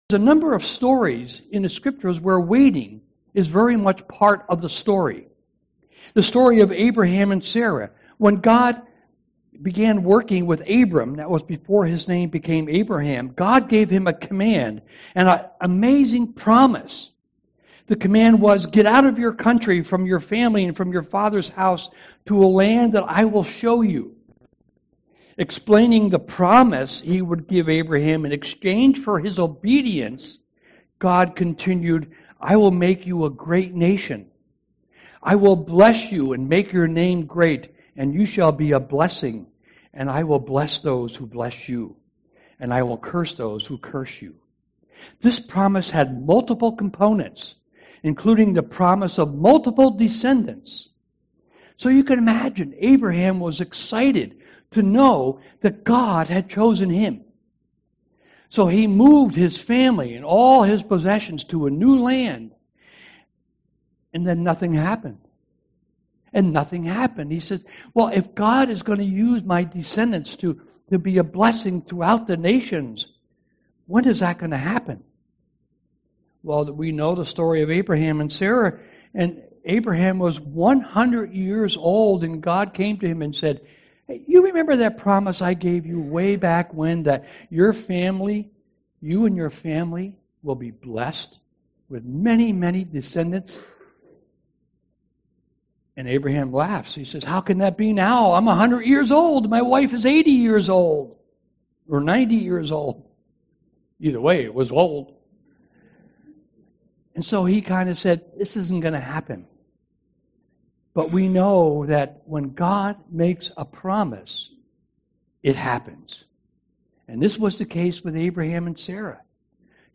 Sermons Archive - Hope Community Church Of Lowell